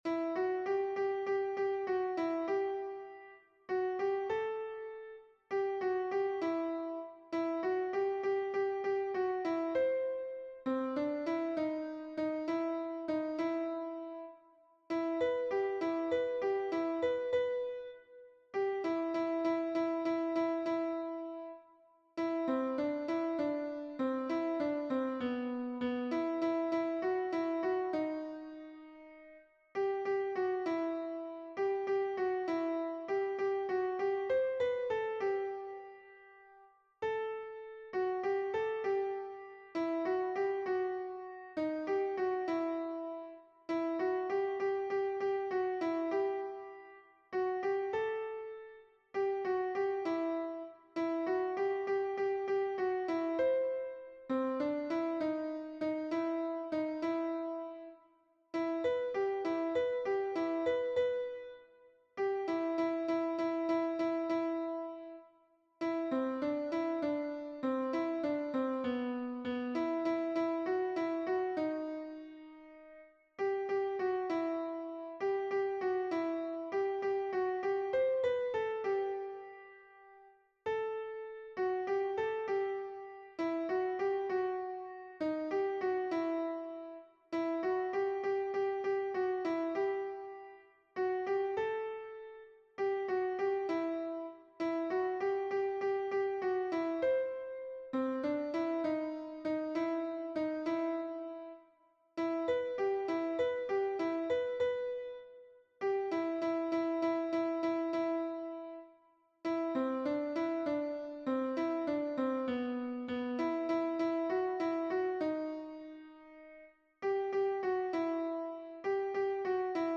MP3 version piano
Alto